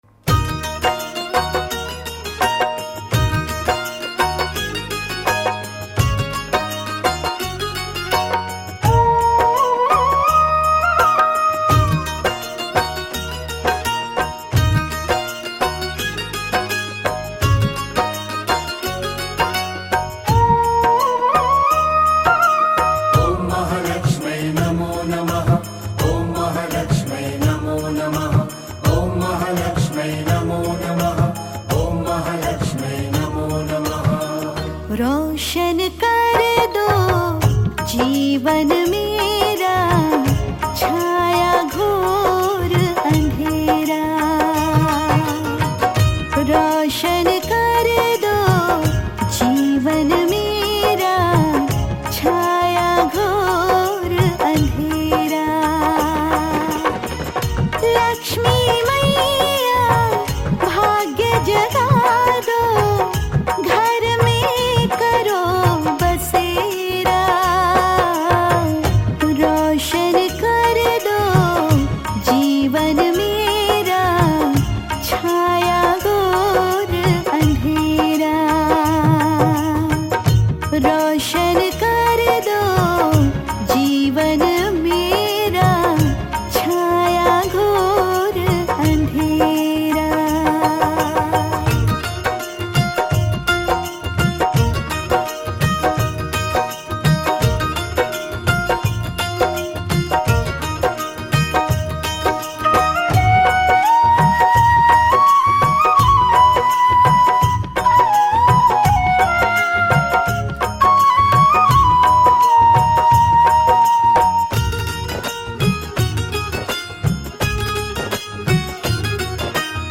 Laxmi Bhajan
Bhakti Hindi Bhajan